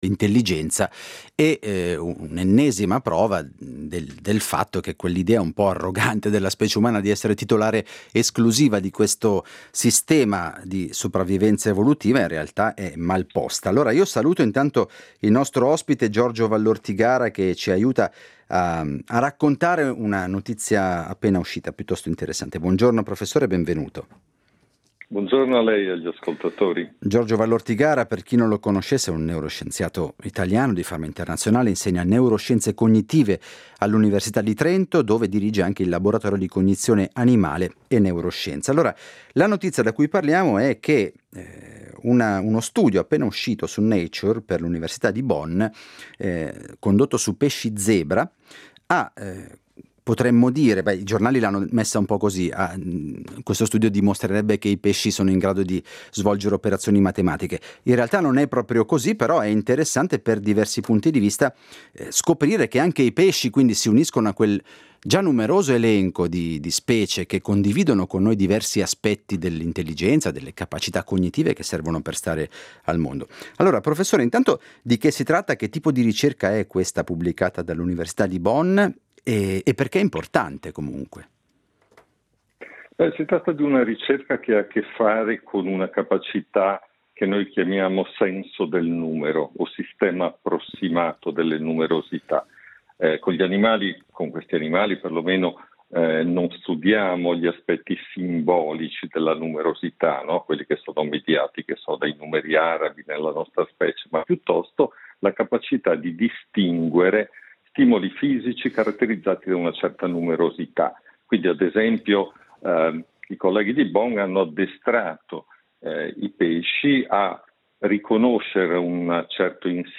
Ne parliamo con Giorgio Vallortigara, neuroscienziato italiano di fama internazionale, professore di Neuroscienze cognitive presso l'Università di Trento, dove dirige il Laboratorio di cognizione animale e neuroscienze.